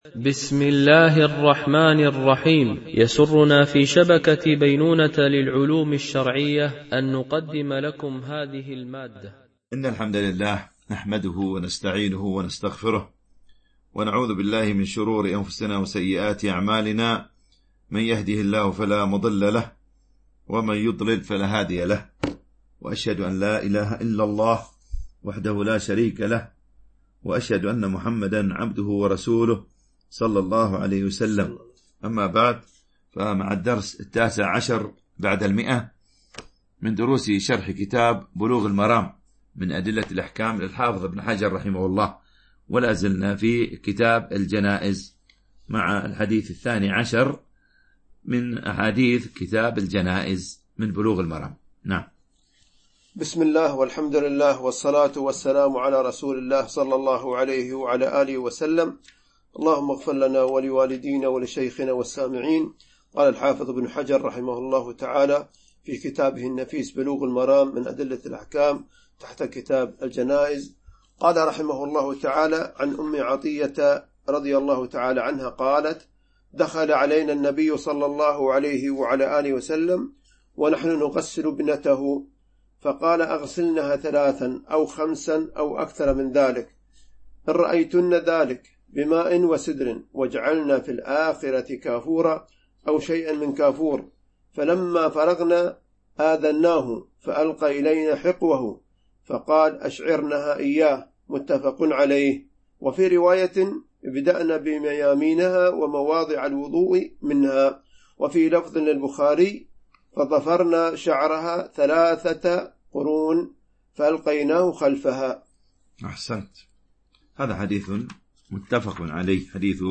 شرح بلوغ المرام من أدلة الأحكام - الدرس 119 ( كتاب الجنائز - الجزء الرابع - 545 - 547 )